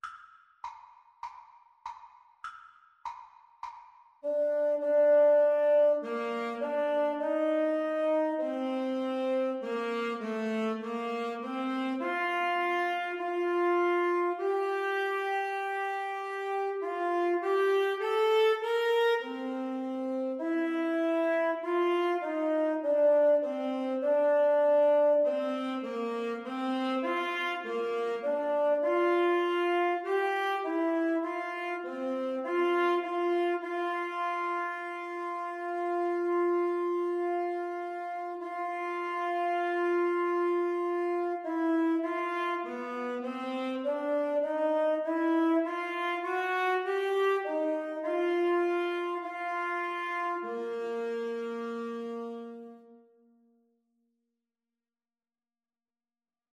Free Sheet music for Alto-Tenor-Sax Duet
4/4 (View more 4/4 Music)
Bb major (Sounding Pitch) (View more Bb major Music for Alto-Tenor-Sax Duet )